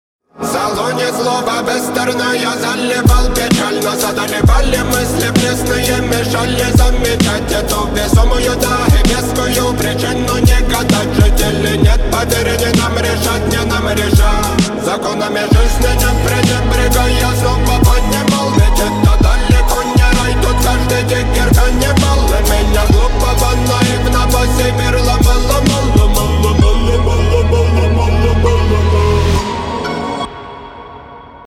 Архив Рингтонов, Рэп рингтоны